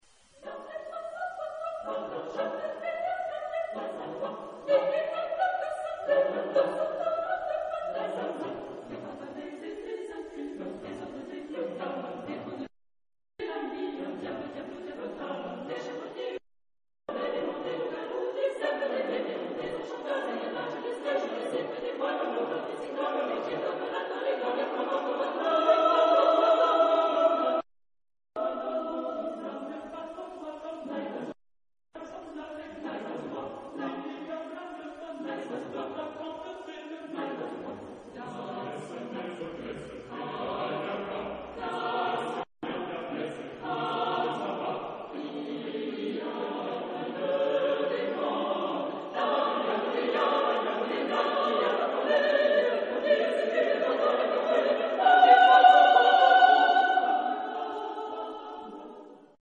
Mood of the piece: fast ; lively
Type of Choir: SATB  (4 mixed voices )
Tonality: A major